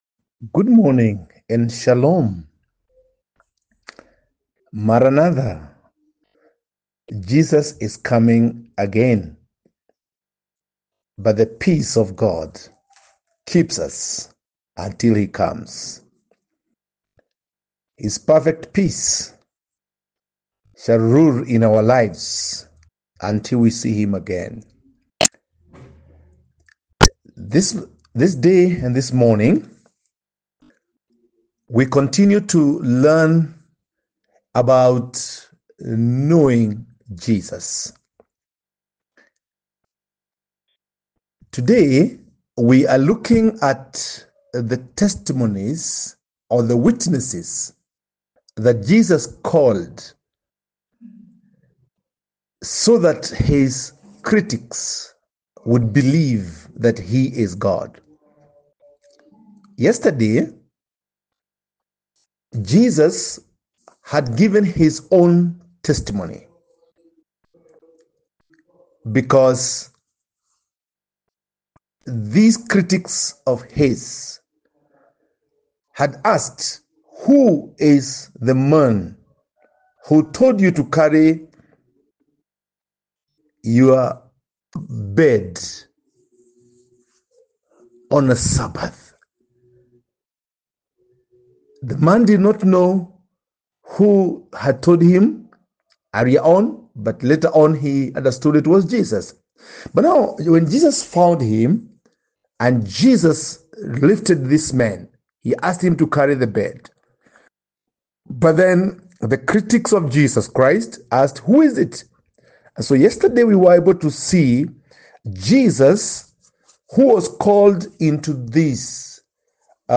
Post Lesson Teaching Summary